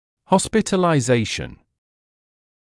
[ˌhɔspɪtəlaɪ’zeɪʃn][ˌхоспитэлай’зэйшн]госпитализация (British English hospitalisation)
hospitalization.mp3